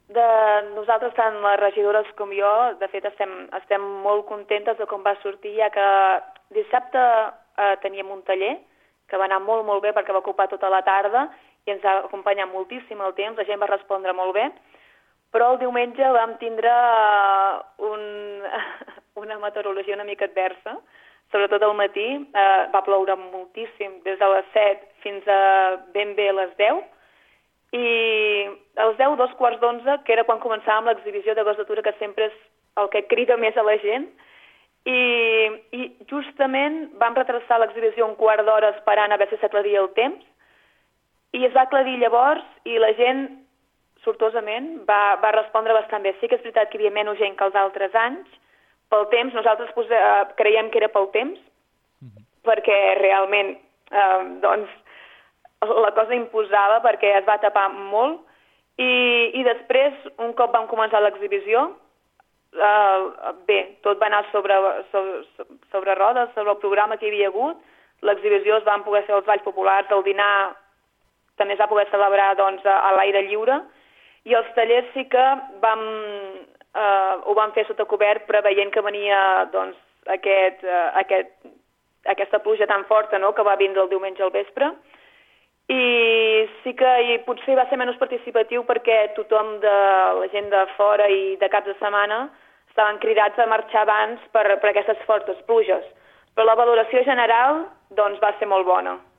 ÀUDIO: L’alcaldessa de Pontils valora l’edició d’aquest 2108
Sara-Janer-Festa-del-Bosc-de-Vallespinosa.mp3